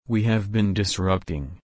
/dɪsˈɹʌpt/